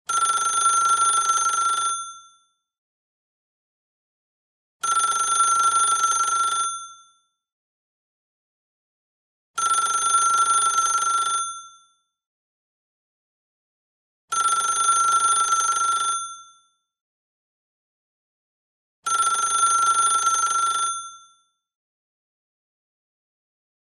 ring-55d2d0c7a71ec16312e8b47eb828fee2a749c32c109247b6ac4389f5b5bde74f.mp3